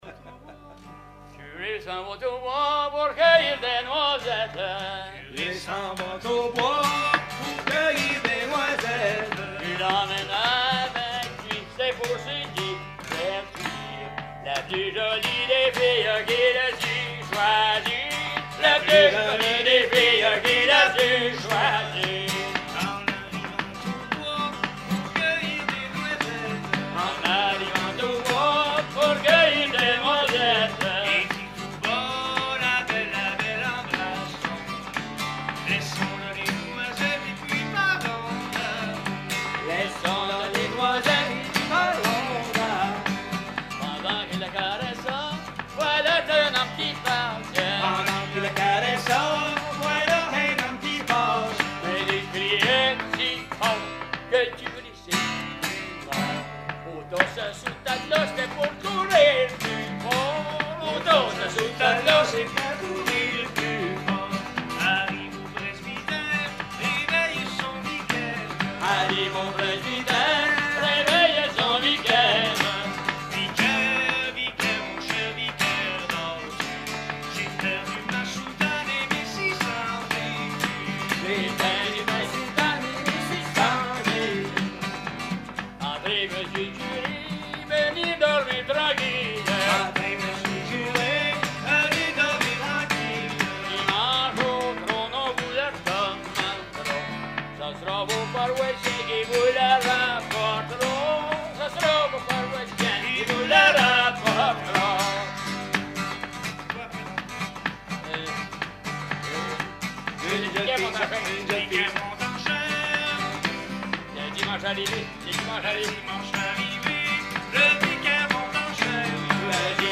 Genre strophique
Veillée québécoise à la Ferme du Vasais
Pièce musicale inédite